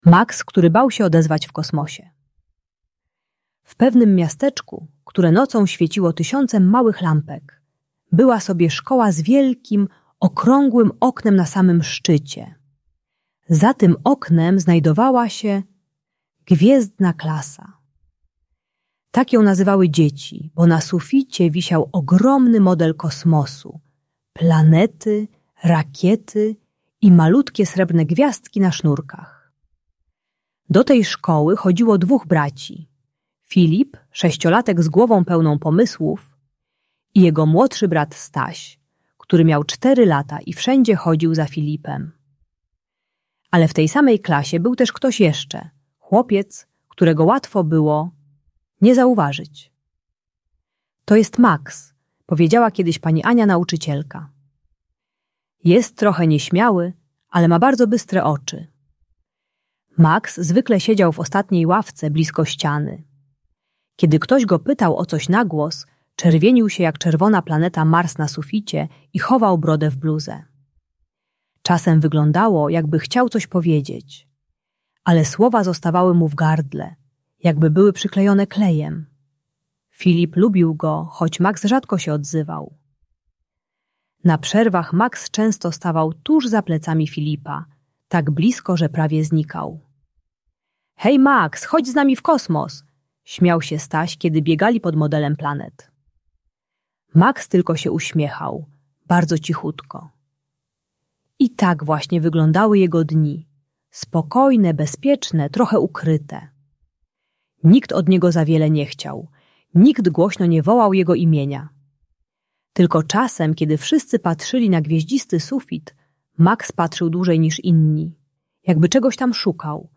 Maks, który bał się odezwać - Lęk wycofanie | Audiobajka